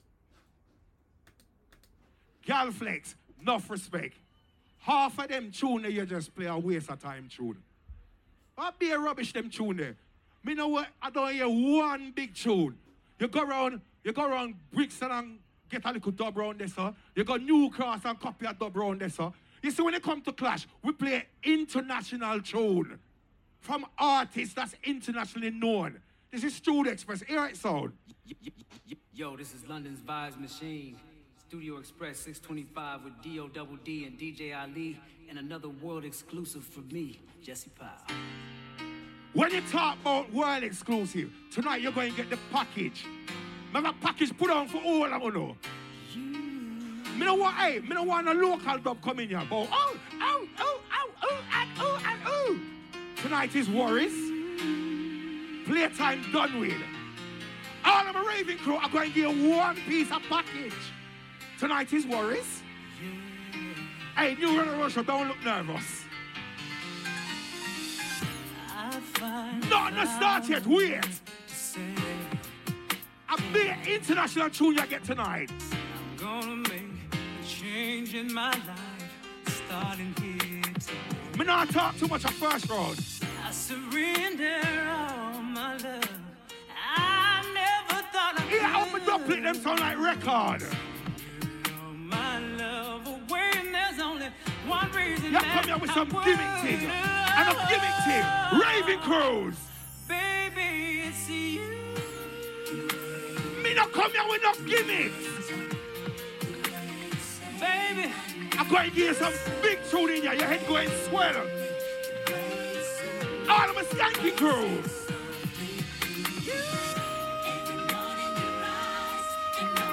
One of the hardest working street sounds formed in the 80’s